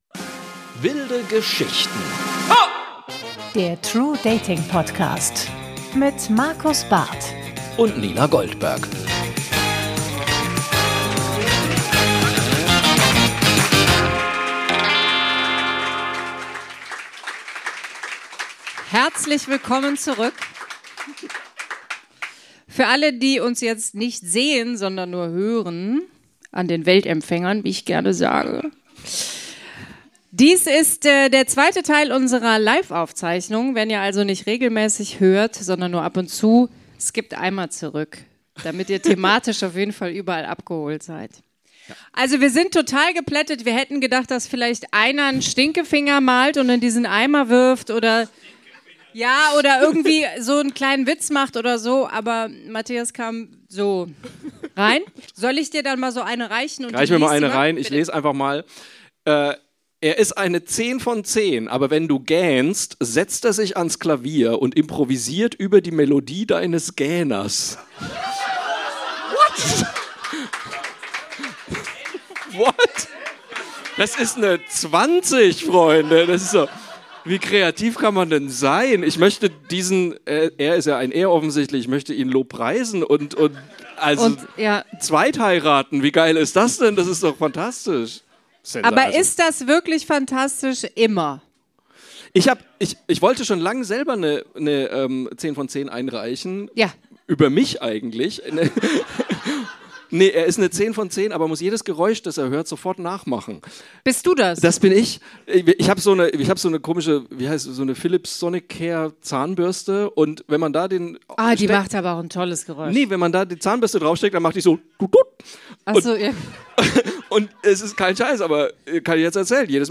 Teil 2 des Mitschnitts unserer ersten Liveshow am 9.12.2025 im Ateliertheater Köln.